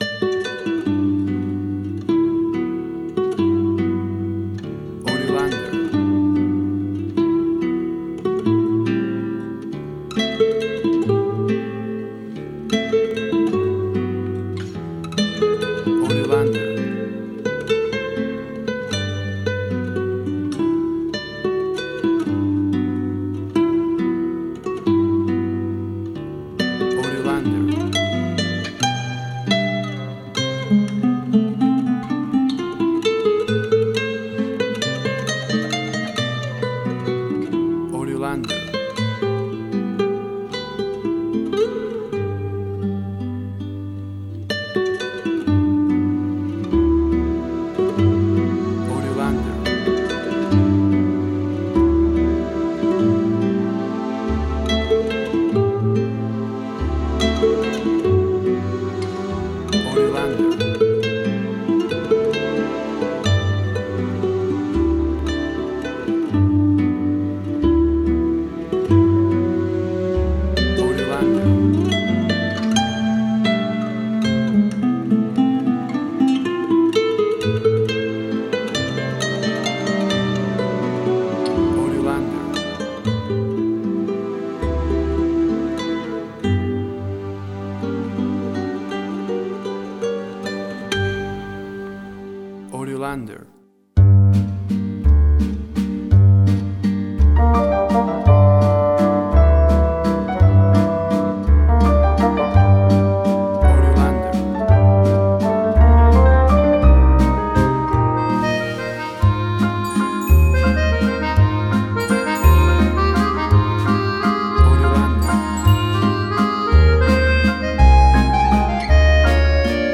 WAV Sample Rate: 24-Bit stereo, 44.1 kHz